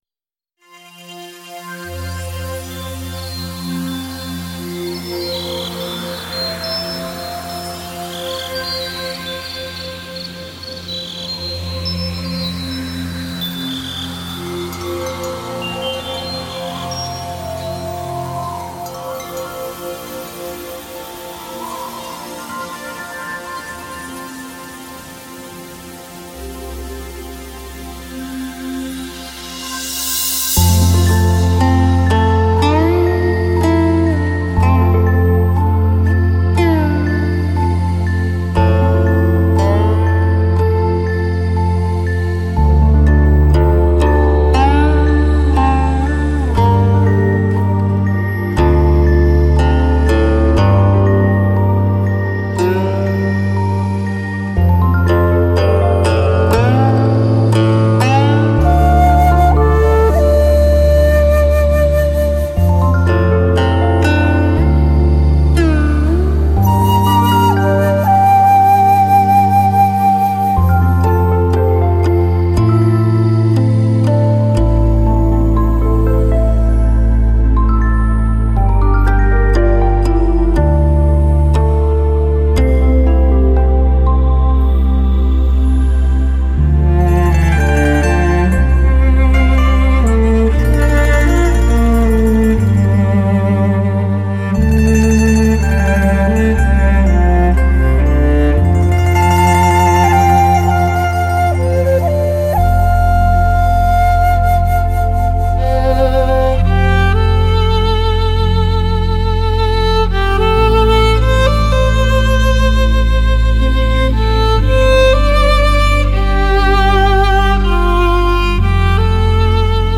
佛音 冥想 佛教音乐 返回列表 上一篇： 落叶满天--《静水禅音》